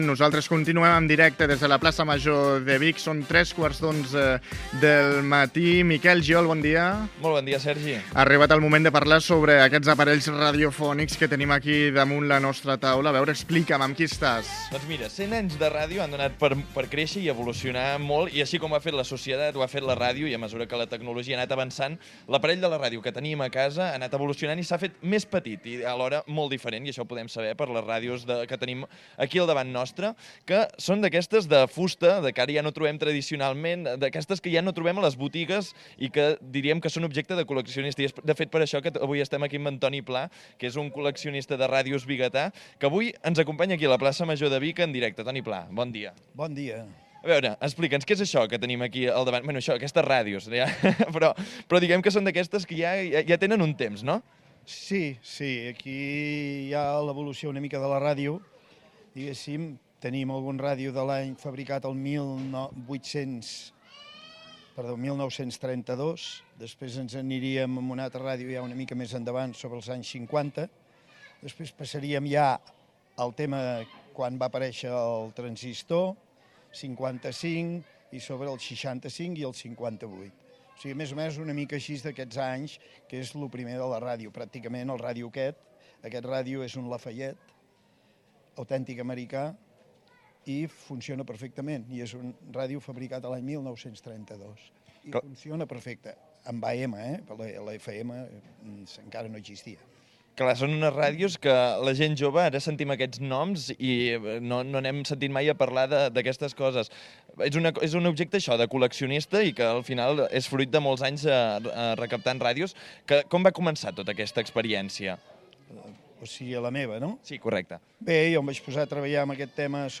Programa fet des de la plaça major de Vic
Entreteniment